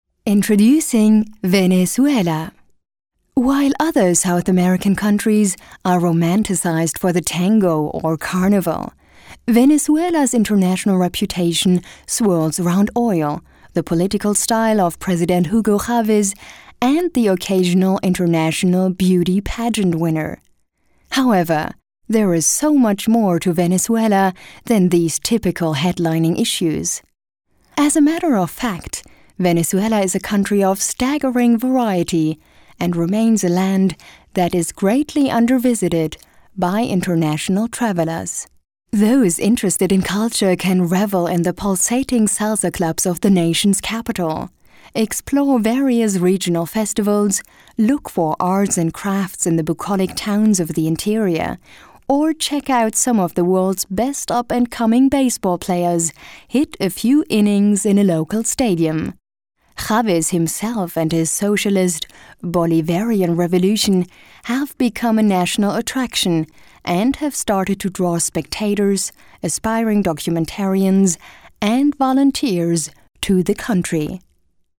Junge bis mittelalte vielseitig einsetzbare Stimme für alle Arten von Audioproduktionen.
deutsche Sprecherin Junge bis mittelalte vielseitig einsetzbare Stimme,
Sprechprobe: Sonstiges (Muttersprache):
german female voice over artist